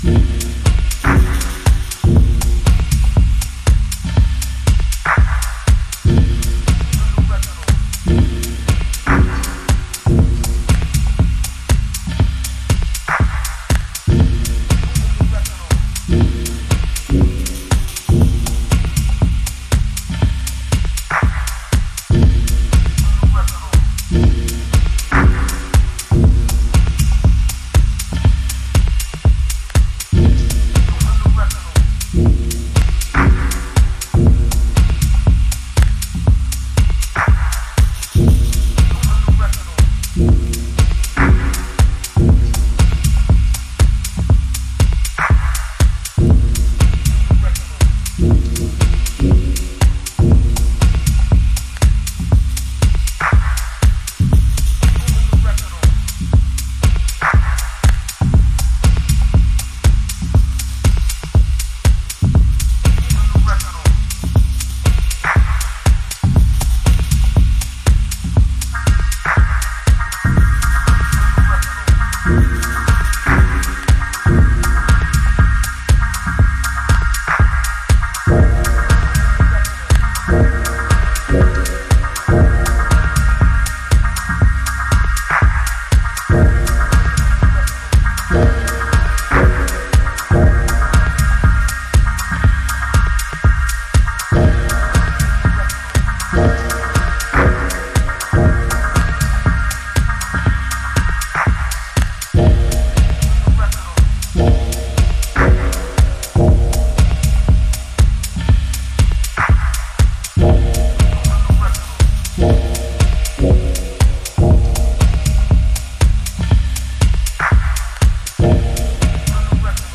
House / Techno
共に冷たい音像。地味渋最前線のダブテック/ディープテクノ。